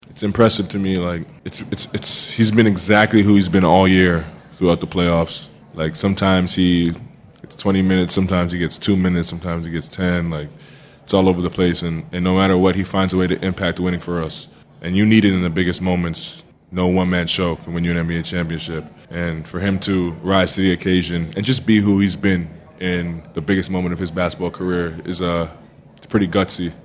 Thunder super star Shai Gilgeous-Alexander says Wiggins consistency helps the team rely on him.